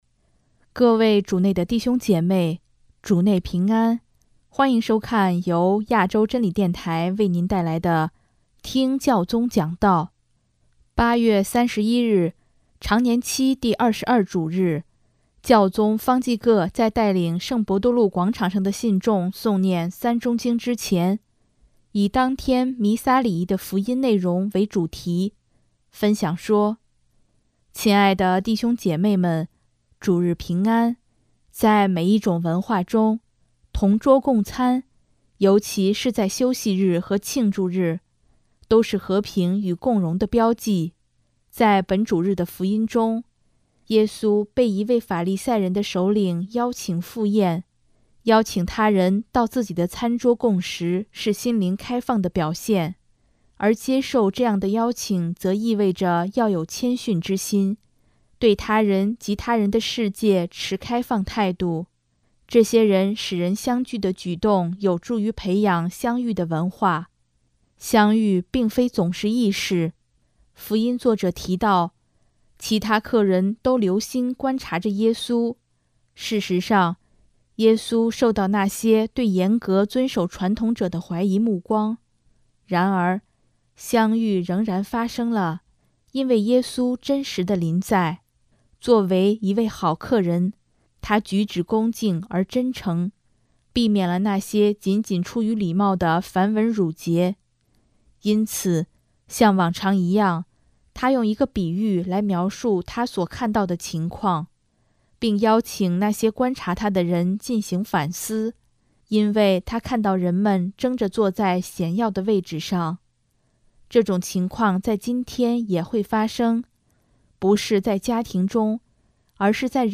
首页 / 新闻/ 听教宗讲道
8月31日，常年期第二十二主日，教宗方济各在带领圣伯多禄广场上的信众诵念《三钟经》之前，以当天弥撒礼仪的福音内容为主题，分享说：